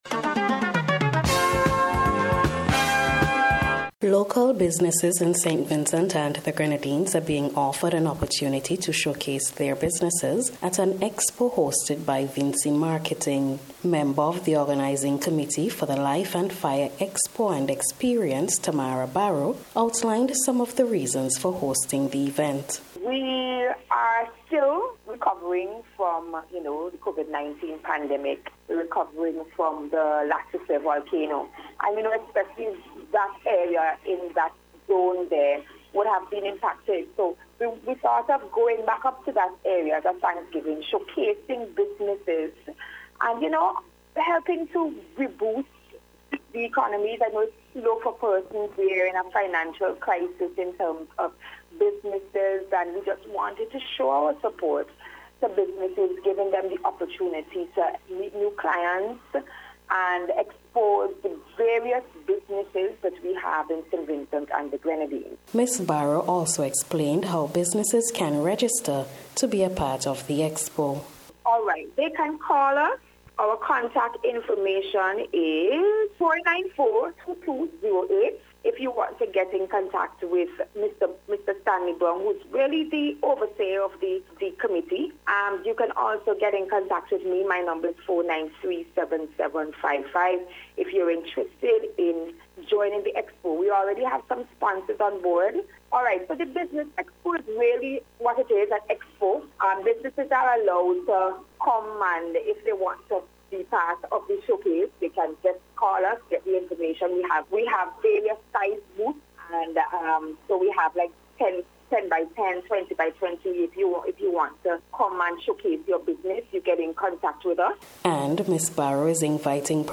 LIFE-AND-FIRE-EXPO-REPORT.mp3